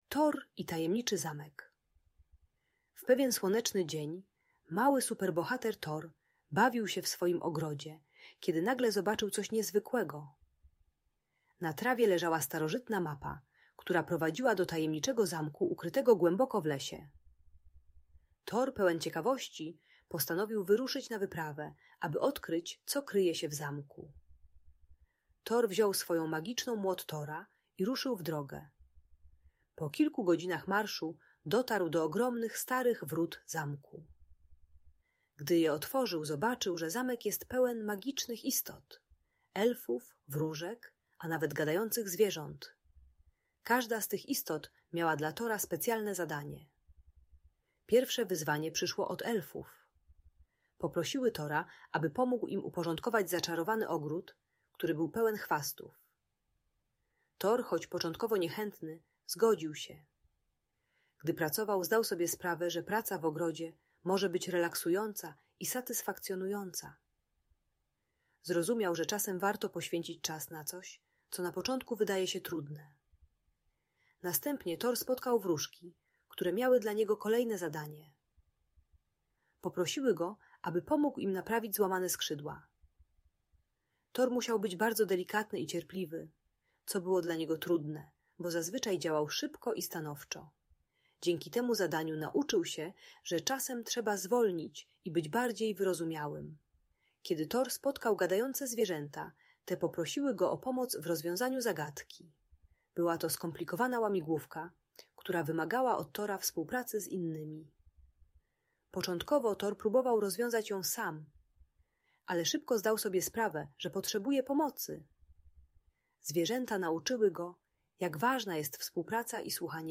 Thor i Tajemniczy Zamek - Audiobajka